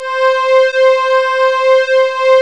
PAD 2.wav